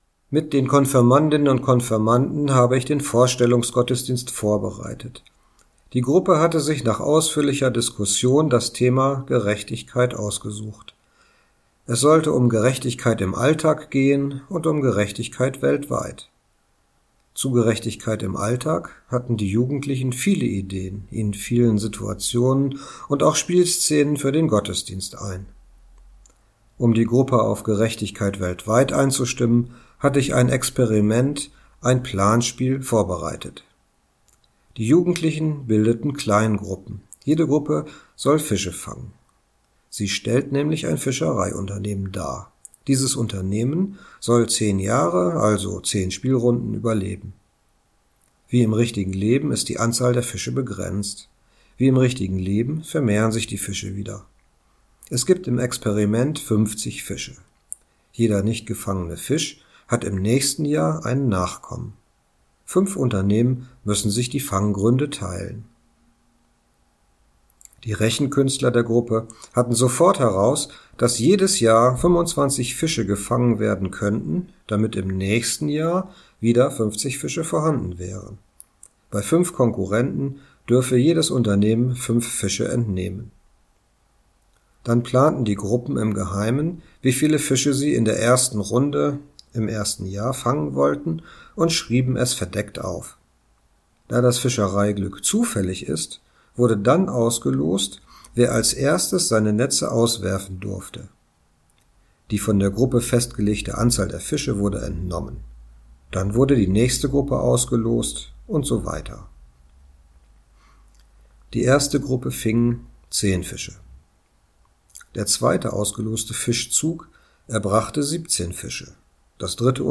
Andacht für den 14. Juni
spielt und singt das das Lied "Lasst uns den Weg der Gerechtigkeit gehn" am Piano.